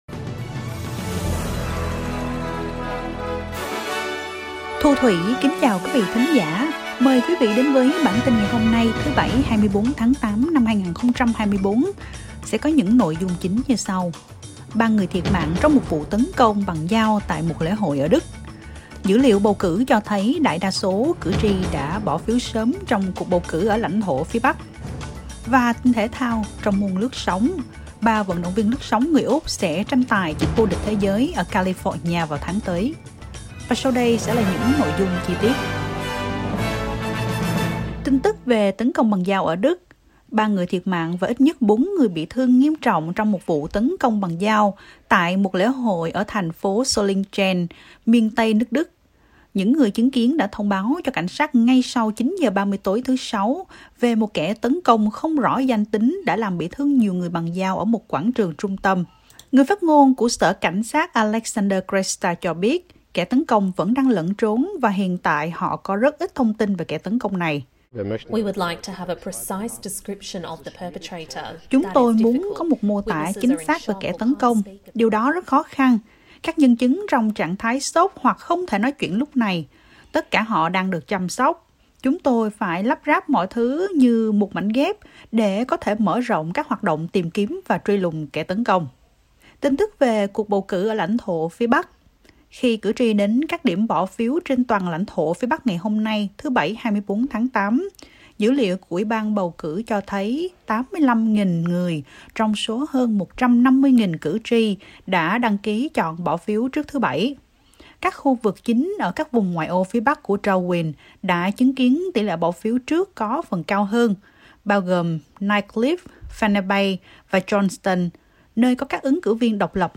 Bản tin nhanh của SBS Tiếng Việt sẽ có những nội dung chính